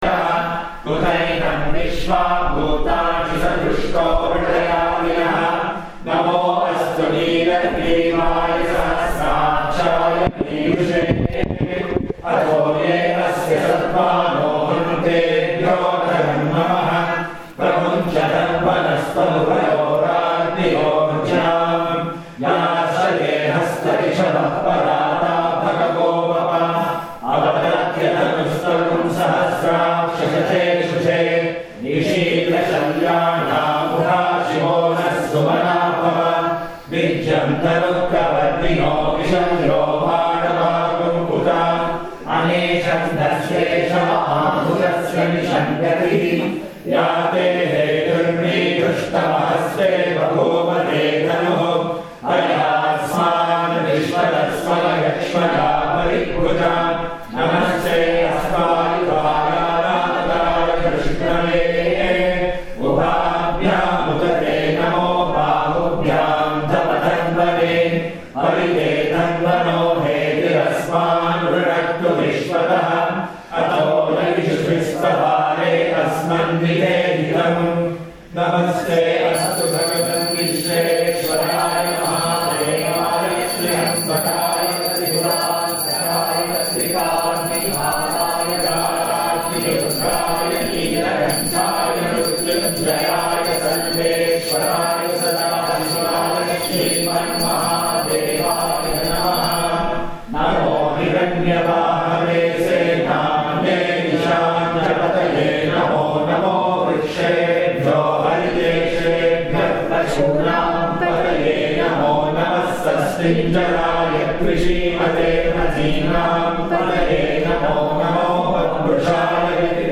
devotees reciting during Aradhana in Ashrama, NY
The program included recitations and bhajans followed by the serving of prasad.